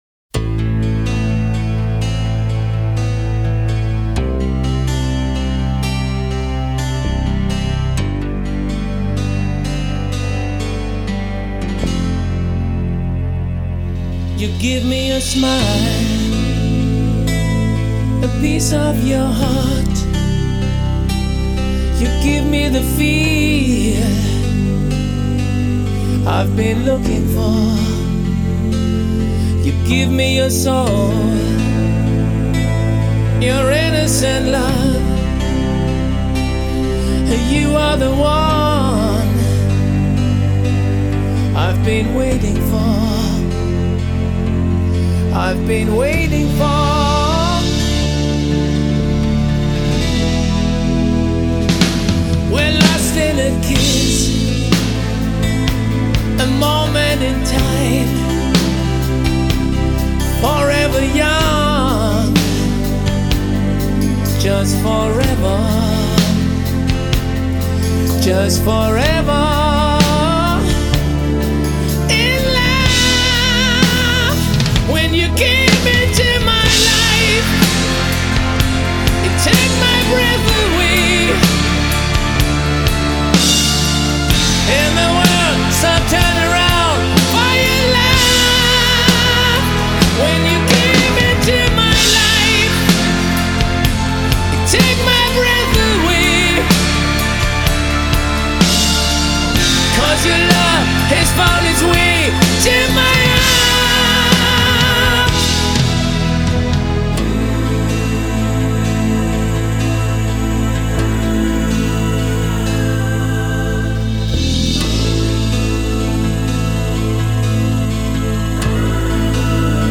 Зарубежный Рок